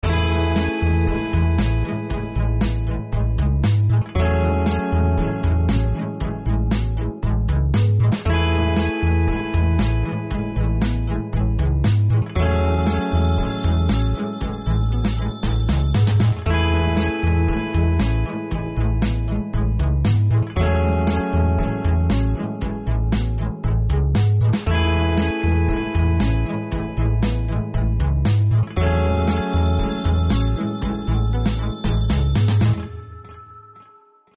Folk Категория: Написание музыки
Музыка chill-folk, любимая музыка))) У меня её больше всего)))